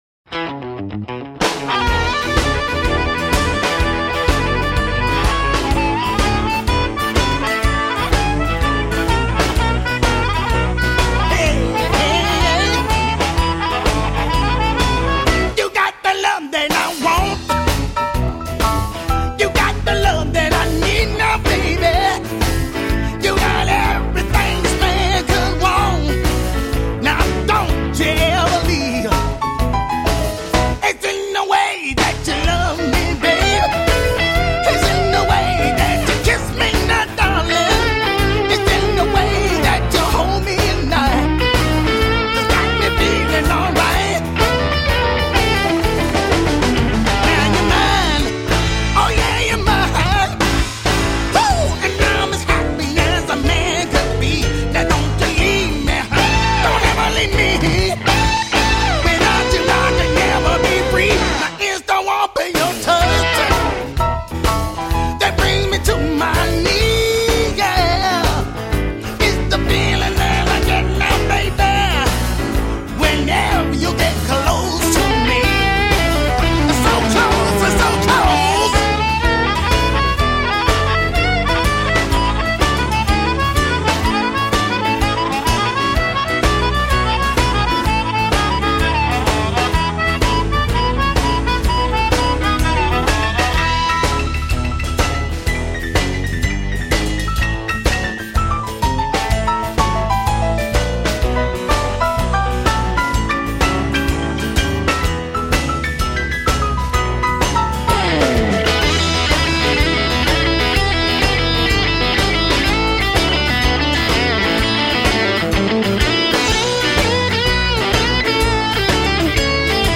Blues guitar legend in the making.